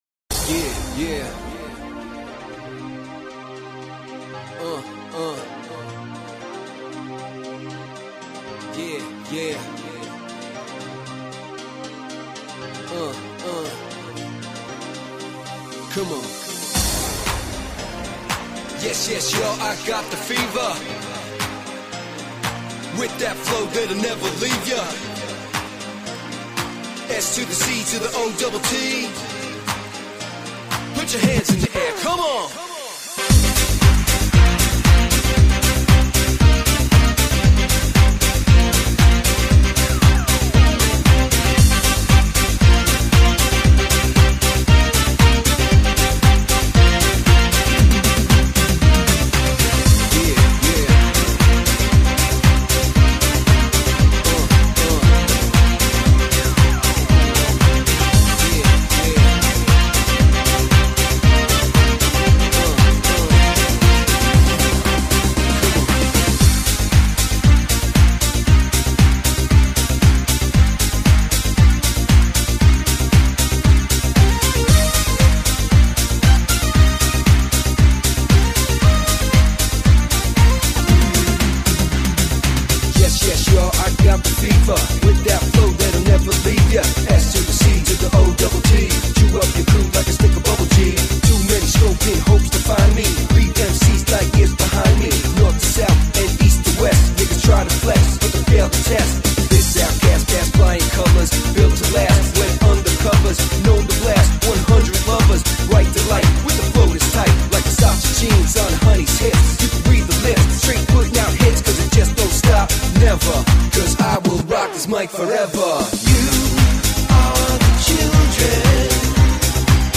Здесь же битр. 192, но спектр у меня вызывает сомнение.